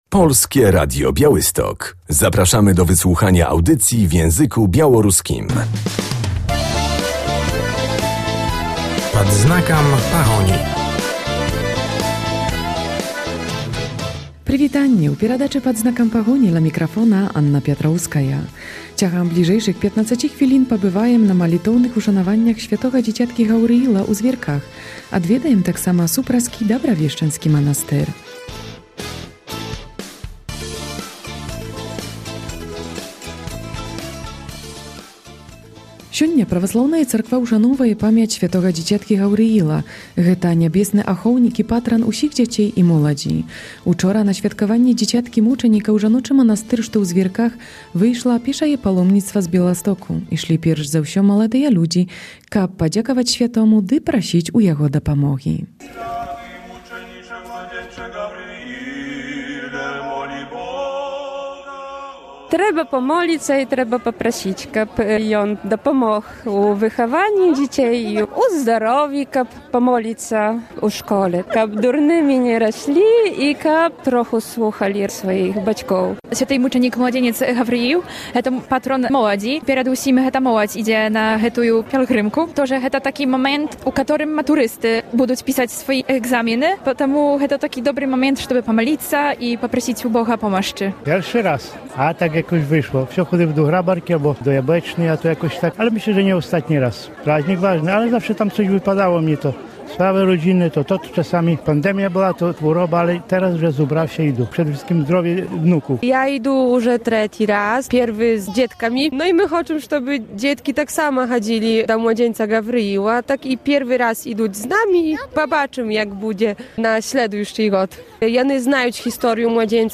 W audycji będziemy na uroczystościach ku czci św. męczennika Gabriela.